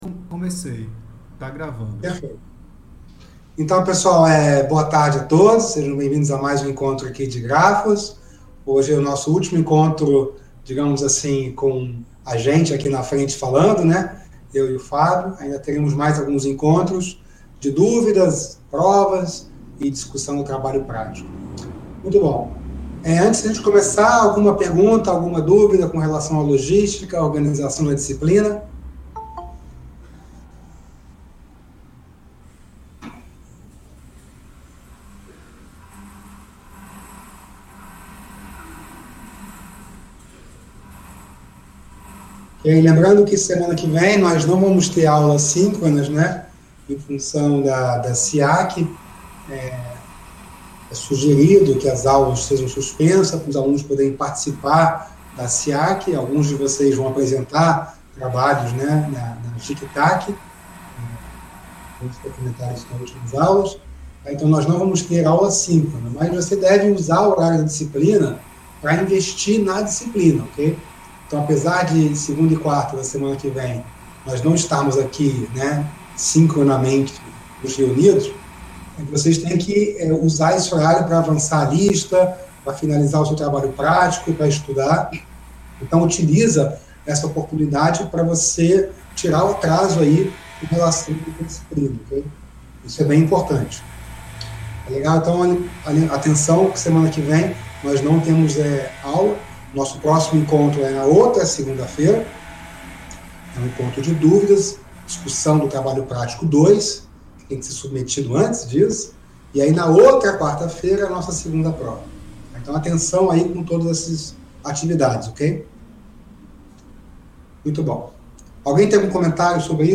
Veja palestra-duelo dos professores sobre Ci�ncia das Redes e Teoria dos Grafos